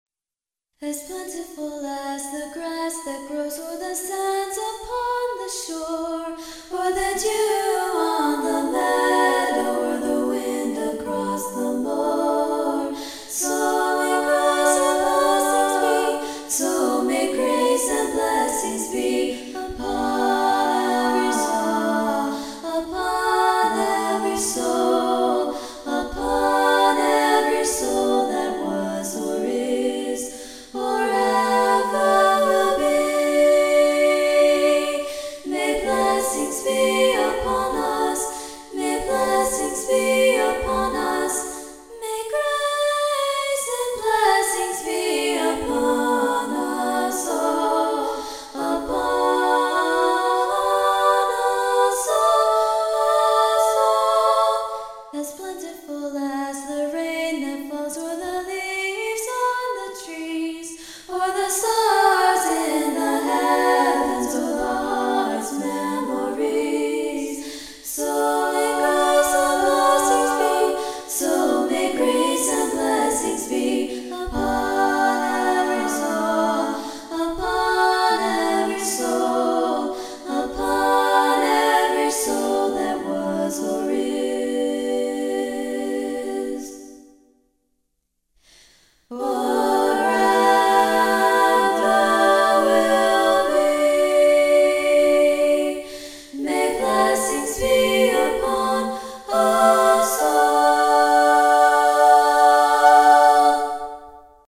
"A Cappello" Vocal and Harmonies